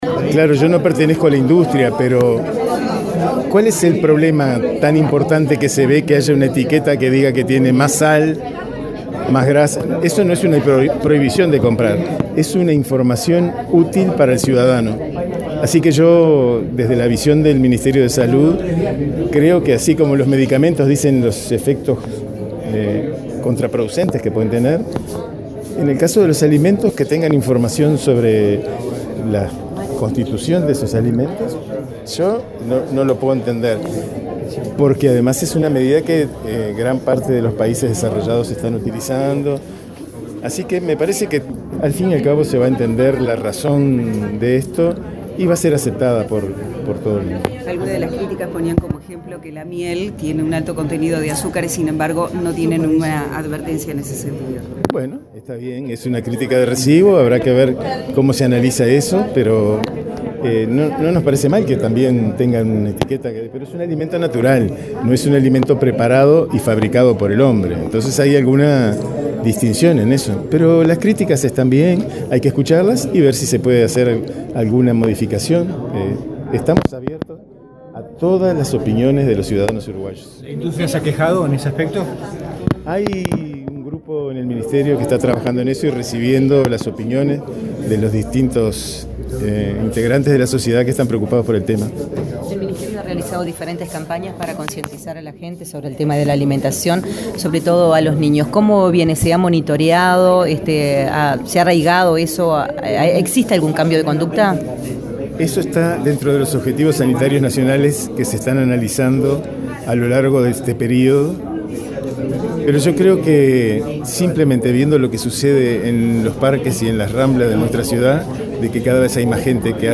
“¿Cuál es el problema en que un alimento tenga una etiqueta que indique que tiene más sal o grasa?”, se preguntó el subsecretario Jorge Quian, al ser consultado por la prensa sobre el etiquetado de alimentos que busca distinguir productos altos en grasas, sales y azúcares, con bajo valor nutricional. La intención es concientizar sobre alimentación saludable, dados los altos índices de obesidad, hipertensión y diabetes.